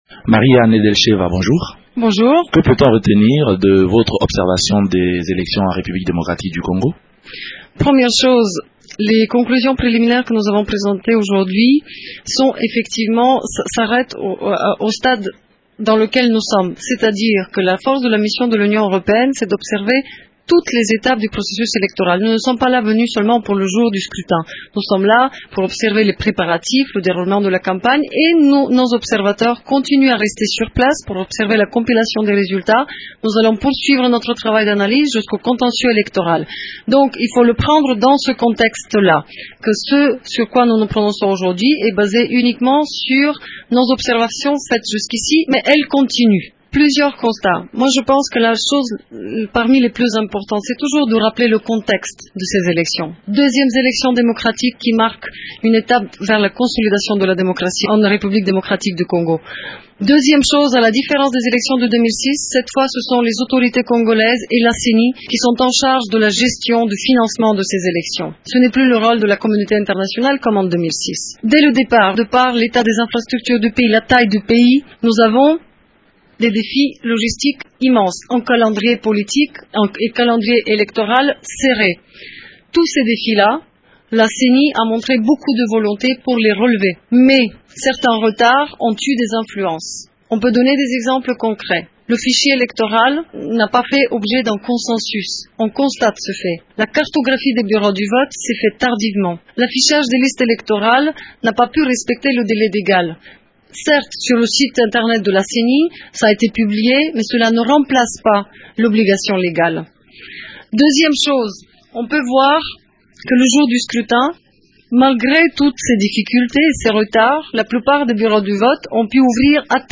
Mariya Nedelcheva commente ce rapport.